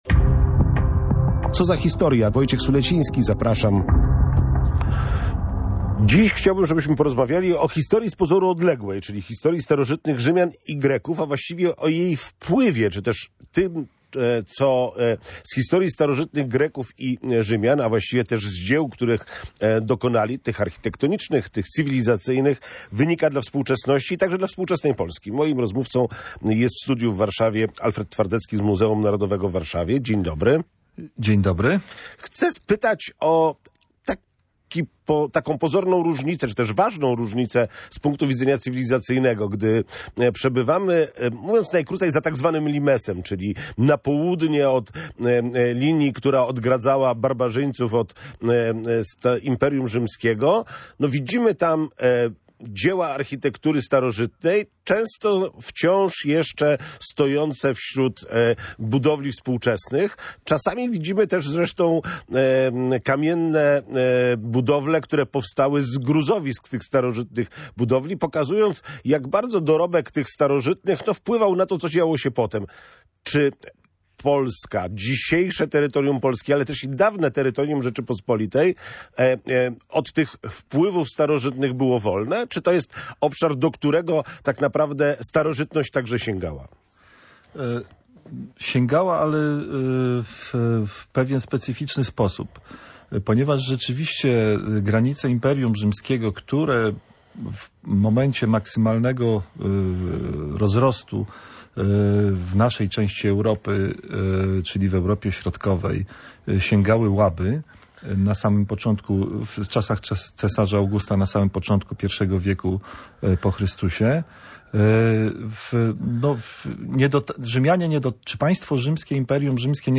O wpływie starożytnych Greków i Rzymian na współczesność rozmawiają